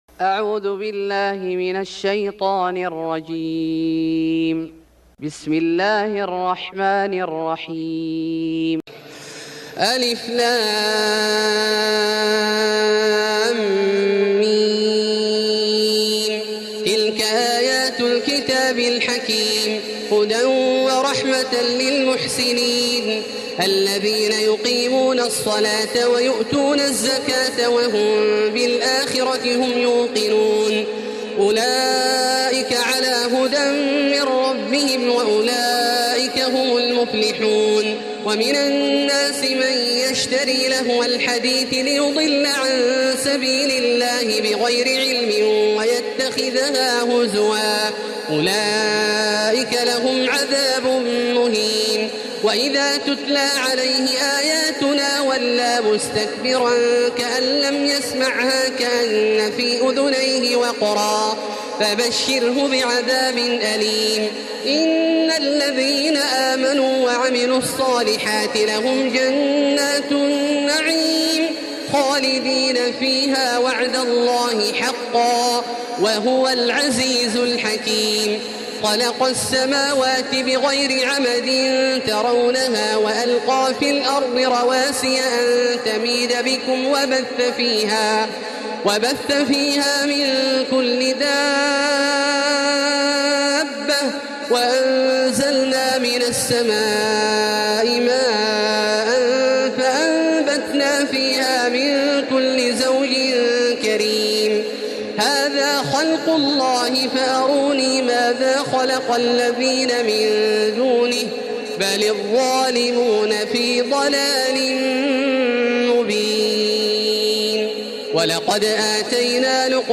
سورة لقمان Surat Luqman > مصحف الشيخ عبدالله الجهني من الحرم المكي > المصحف - تلاوات الحرمين